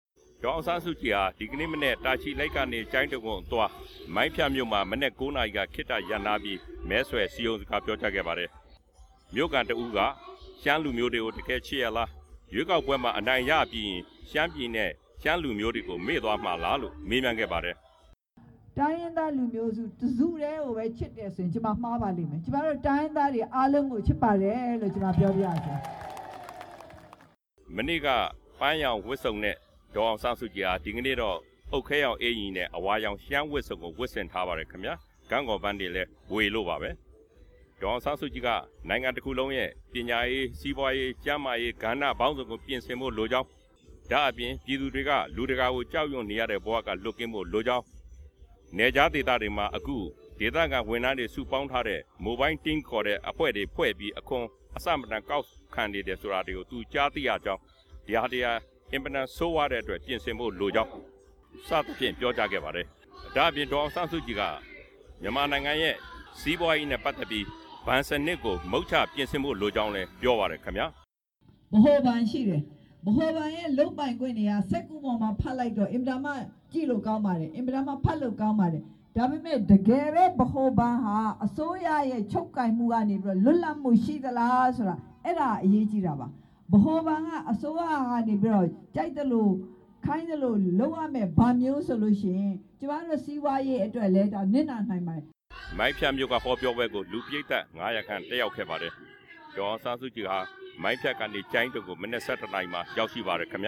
ဒေါ်အောင်ဆန်းစုကြည် ဟောပြောပွဲ(မိုင်းဖြတ်မြို့)